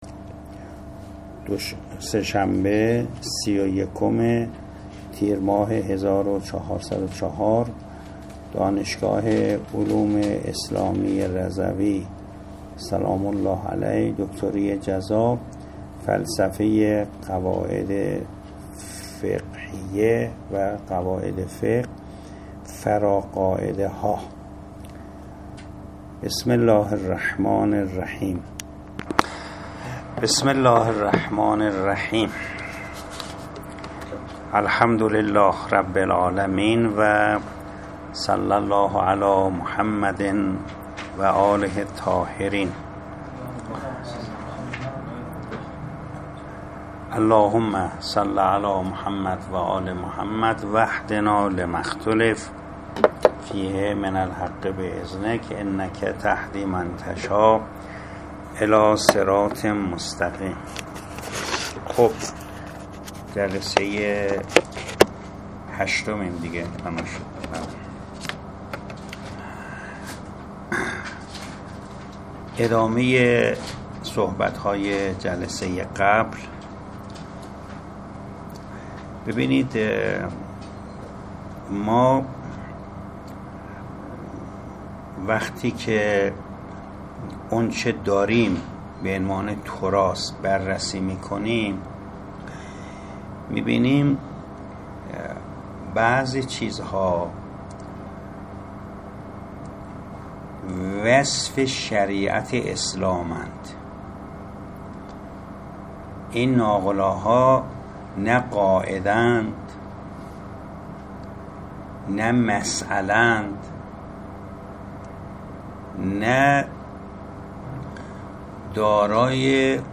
دانشگاه علوم اسلامی رضوی فلسفه قواعد فقه جلسه هشتم ادامه گفتگو از فراقاعده ها همراه با کنفرانس دانشجويان